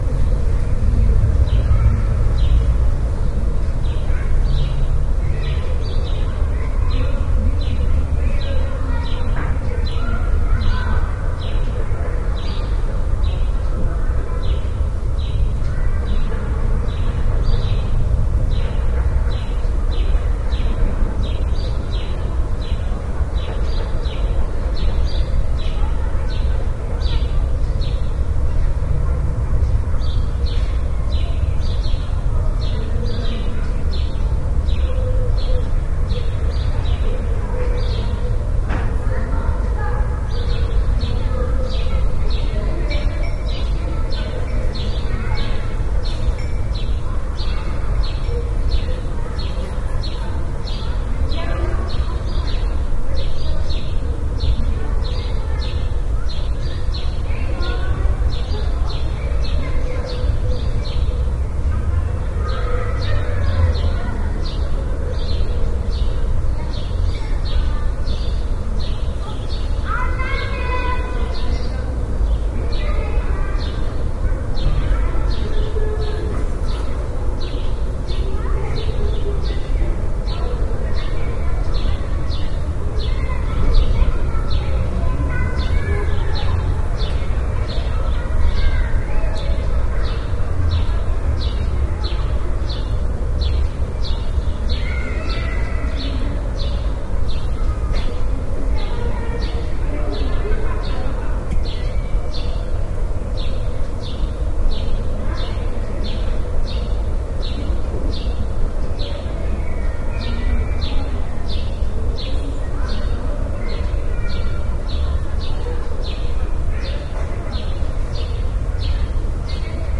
氛围鸟
描述：鸟儿不停地鸣叫和吹口哨。背景噪音或氛围。
标签： 背景声 小鸟的鸣叫 原子 OWI 氛围 环境 背景 啁啾 鸟类 口哨 氛围 原子 口哨 一般噪声 气氛
声道立体声